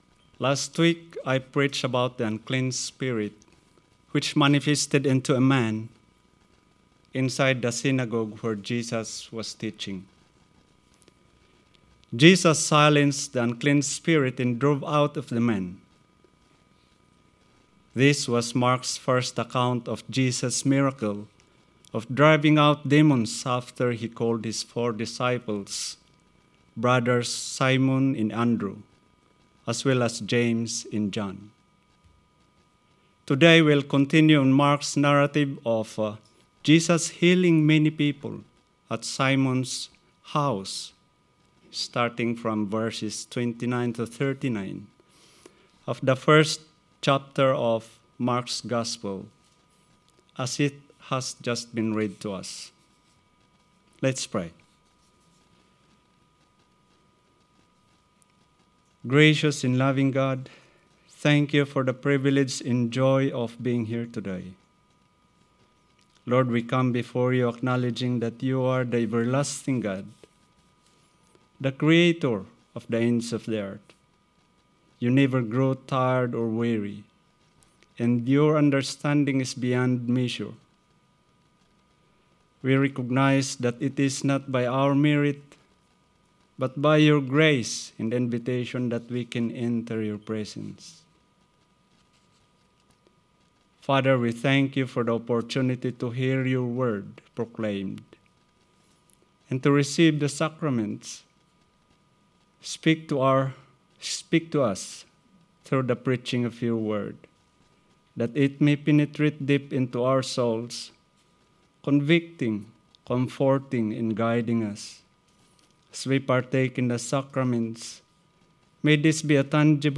Sermon 4th February